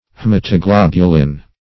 Haematoglobulin \H[ae]m`a*to*glob"u*lin\, n.